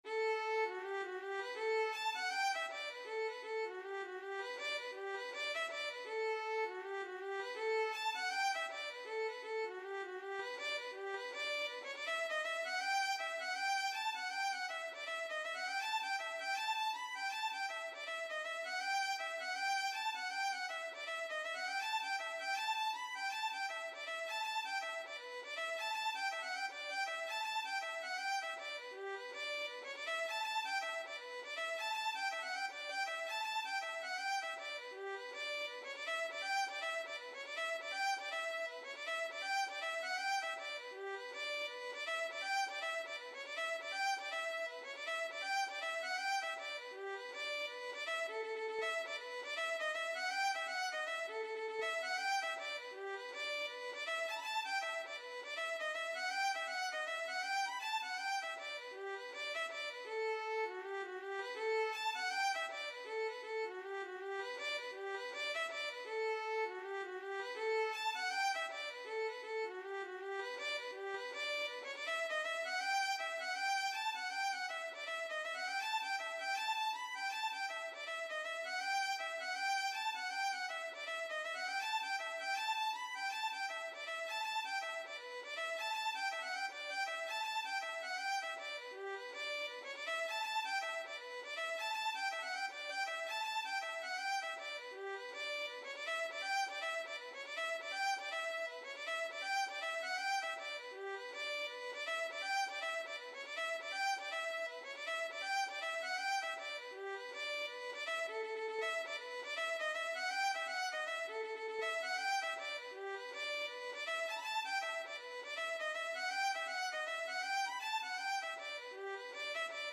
Traditional Trad. Jolly Tinker, The (Irish Trad) Violin version
D major (Sounding Pitch) (View more D major Music for Violin )
4/4 (View more 4/4 Music)
Violin  (View more Intermediate Violin Music)
Traditional (View more Traditional Violin Music)
Reels
Irish